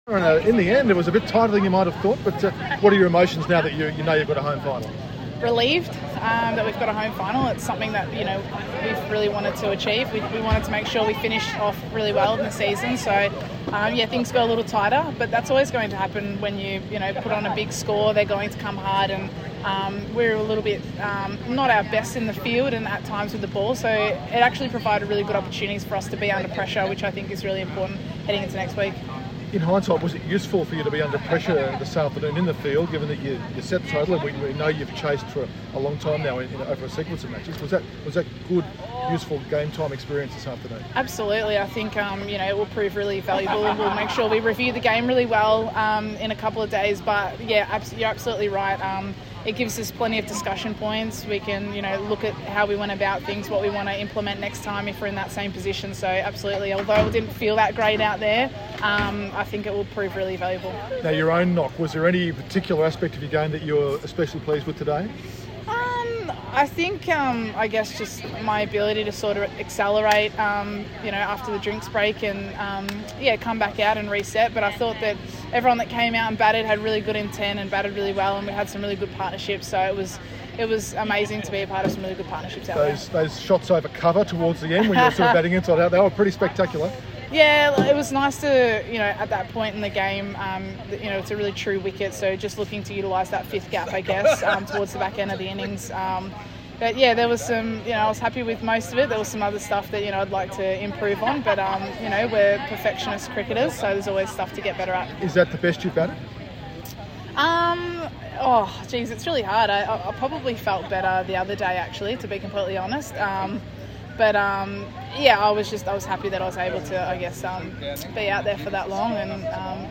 POTM Elyse Villani speaking post match following Tasmania’s win over WA today